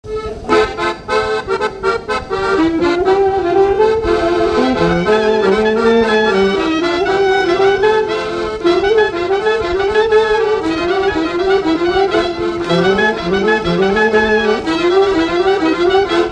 Chaillé-sous-les-Ormeaux
Chants brefs - A danser
danse : polka
Pièce musicale inédite